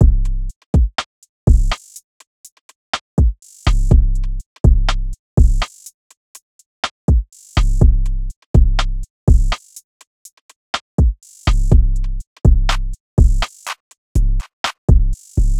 GANG GANG Drum Loop (123 BPM)